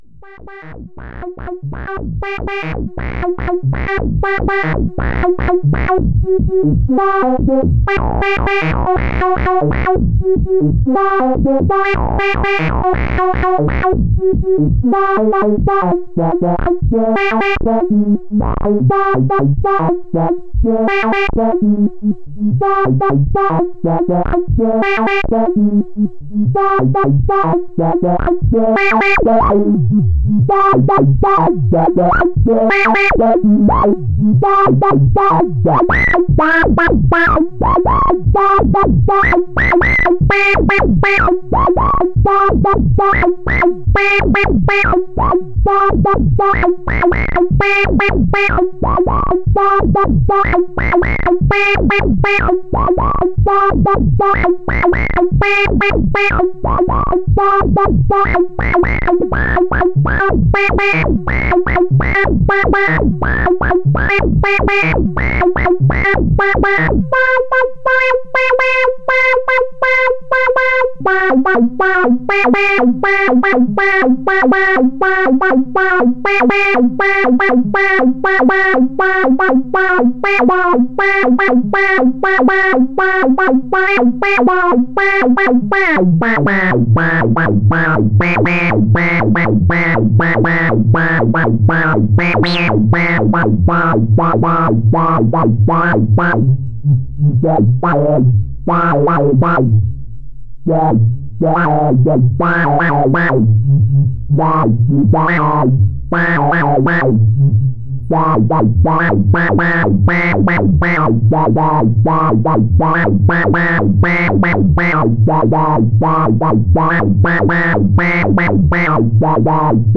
here is some audio of the timetable in action. a basic oscillator and filter are used with the timetable in this demo. the pitch of the oscillator, frequency of the filter, and the divider within the timetable are all cv'd from different voltages available on the timetable.